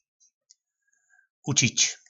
Slovník nářečí Po našimu
Učit (se) - Učič (se)